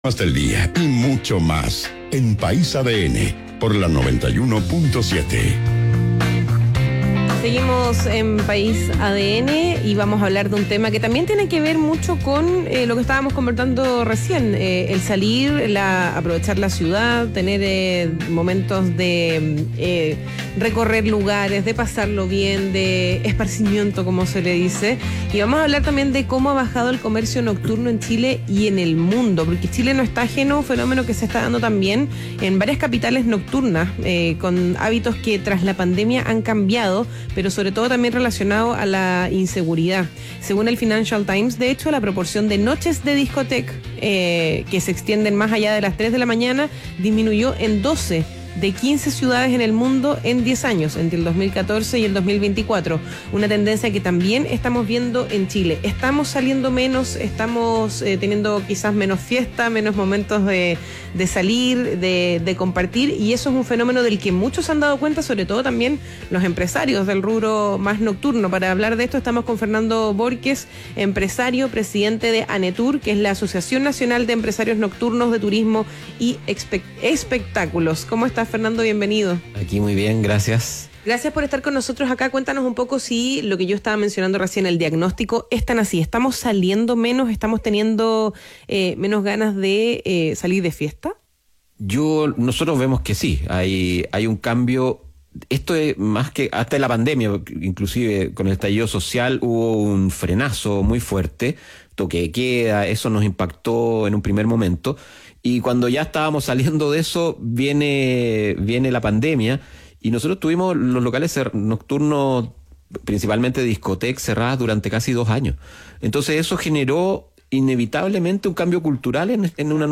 País ADN - Entrevista